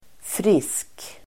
Uttal: [fris:k]